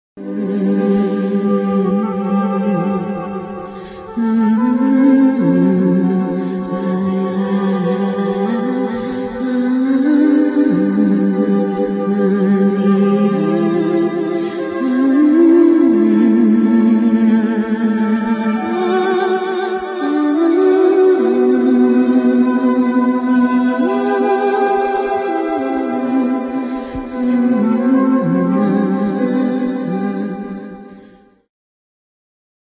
harmonic slow instr.